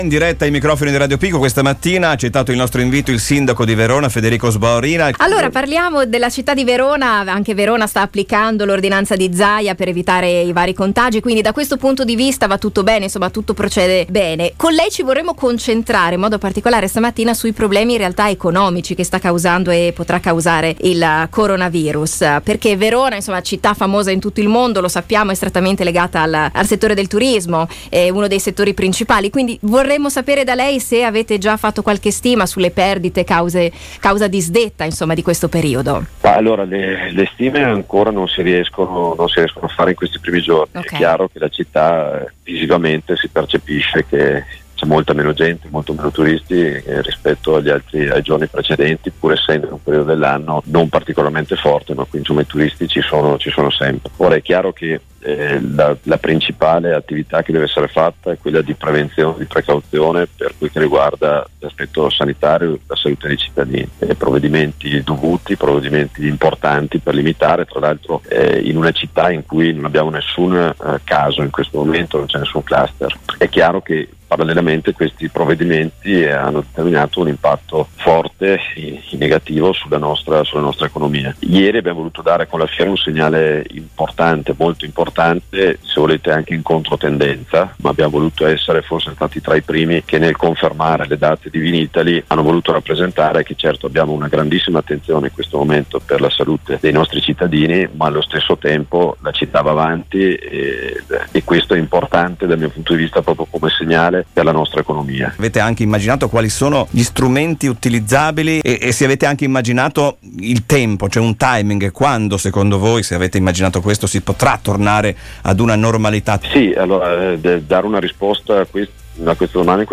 INTERVISTA AL SINDACO DI VERONA SUL CORONAVIRUS
Al nostro microfono il sindaco di Verona, Federico Sboarina, sull’importanza delle misure restrittive adottate in tema di Coronavirus, e sulle possibili conseguenze economiche che avranno sulla città: